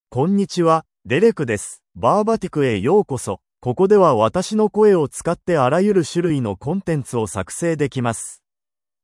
MaleJapanese (Japan)
DerekMale Japanese AI voice
Derek is a male AI voice for Japanese (Japan).
Voice sample
Derek delivers clear pronunciation with authentic Japan Japanese intonation, making your content sound professionally produced.